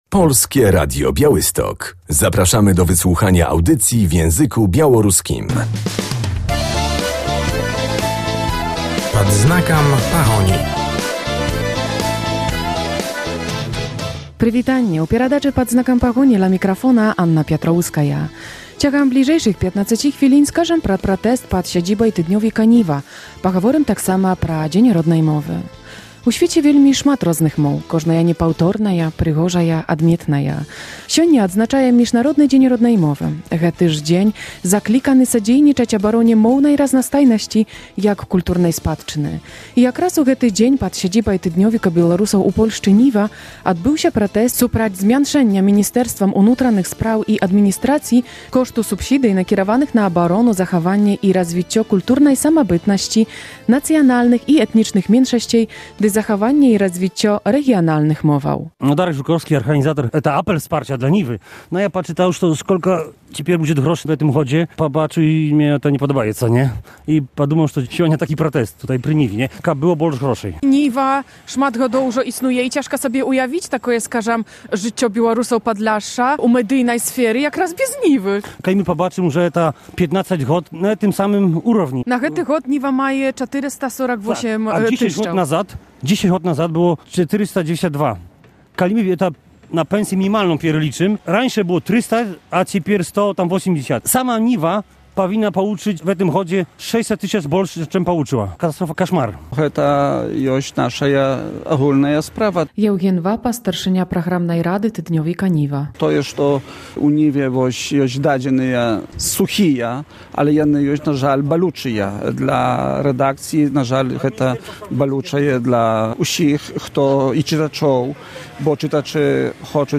W audycji będziemy na apelu wsparcia dla tygodnika Białorusinów w Polsce "Niwa".